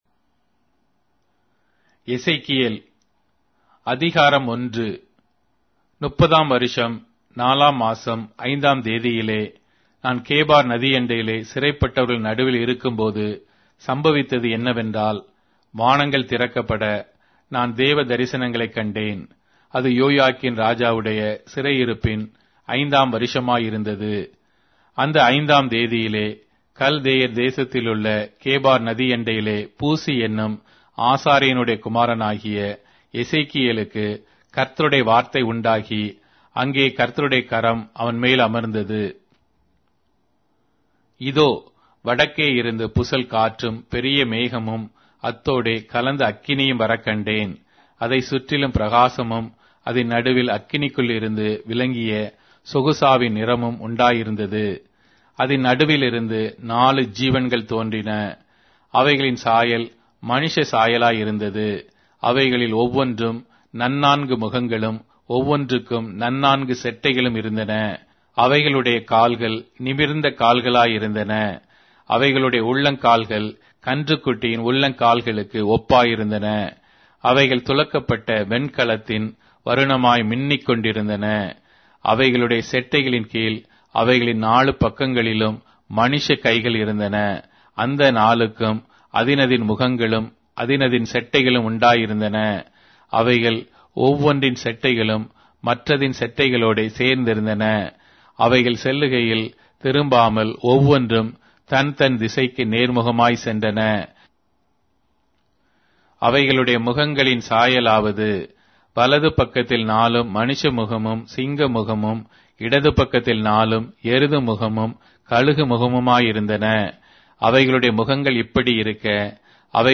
Tamil Audio Bible - Ezekiel 8 in Mhb bible version